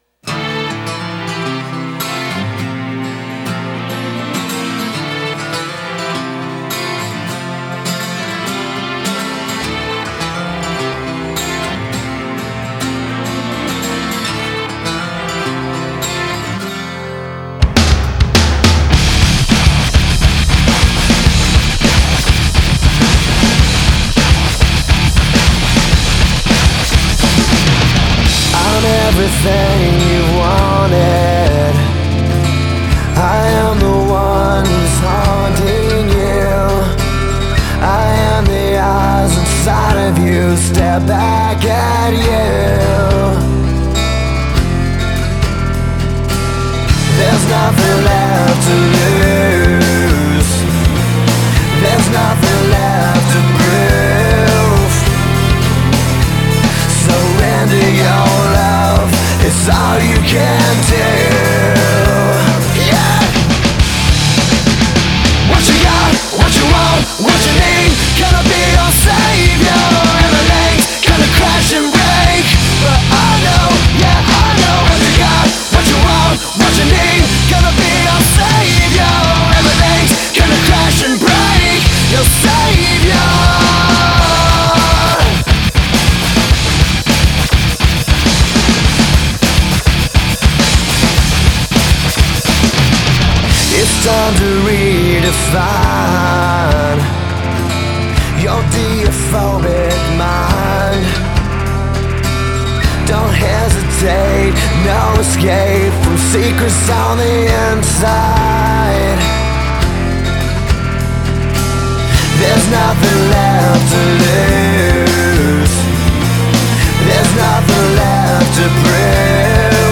Категория: Альтернатива